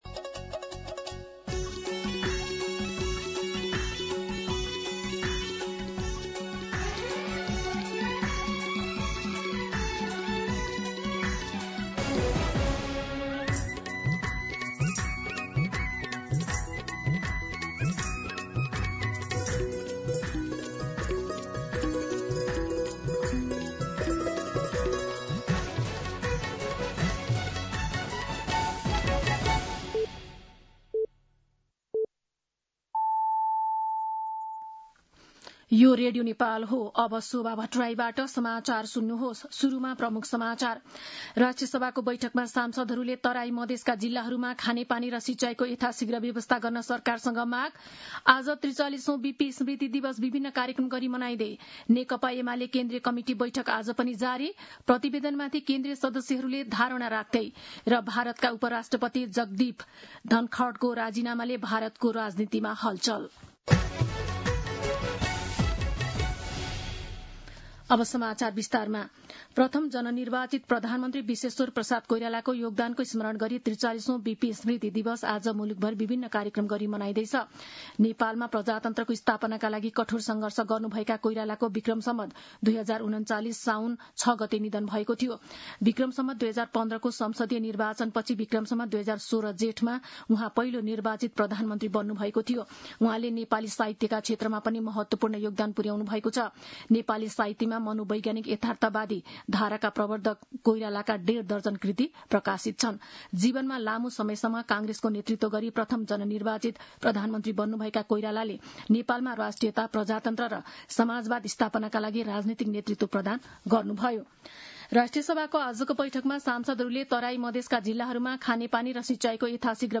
An online outlet of Nepal's national radio broadcaster
दिउँसो ३ बजेको नेपाली समाचार : ६ साउन , २०८२